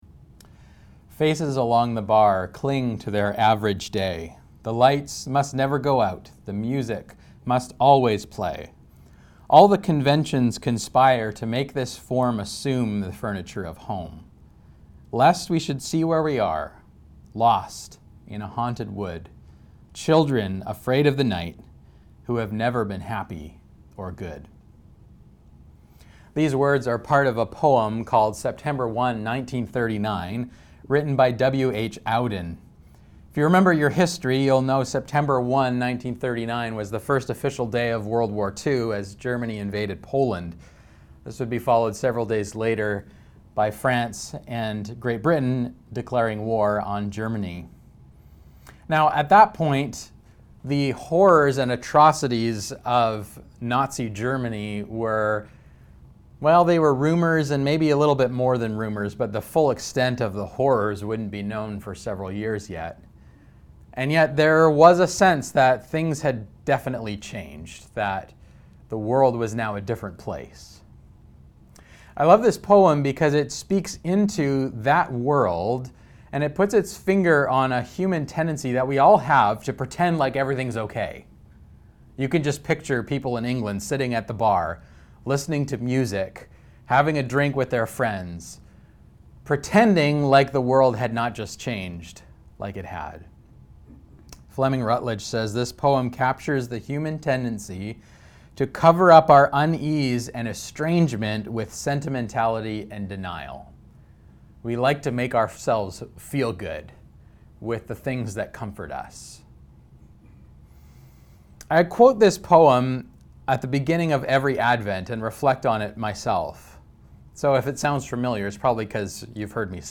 Current Sermon Prophetic Hope While the hustle and bustle of the Christmas season distracts us from the difficult realities of the world, Advent invites us to find hope in the midst of the darkness through the celebration of the coming of Jesus, the light of the world.